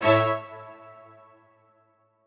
admin-leaf-alice-in-misanthrope/strings34_1_017.ogg at a8990f1ad740036f9d250f3aceaad8c816b20b54